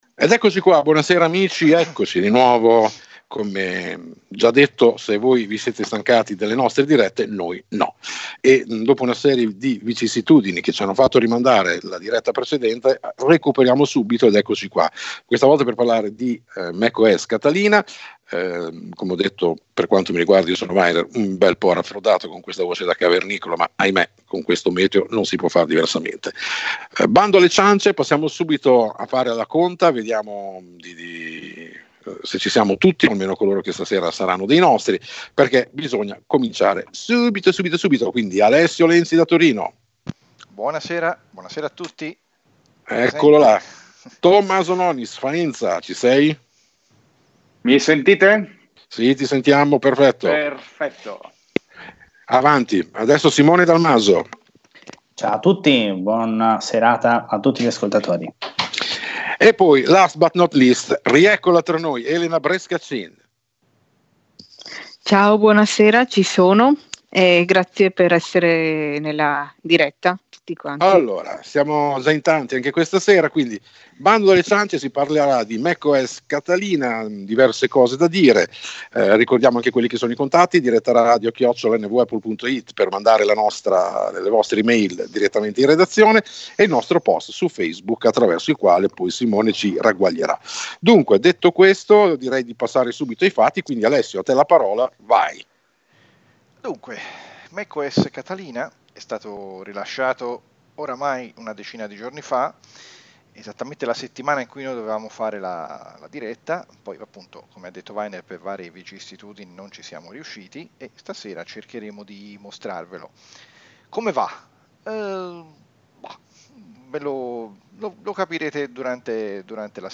Prima parte della diretta sull'accessibilità e l'usabilità di Catalina. In questa sezione troverete: Personalizzazione livello punteggiatura, controllo vocale in italiano, gestione file e dispositivi dopo il termine del supporto e della presenza di iTunes, l'app musica, modifiche nel braille, domande degli ascoltatori. Ci scusiamo per l'audio disturbato nella seconda parte del podcast, tutto è assolutamente comprensibile comunque.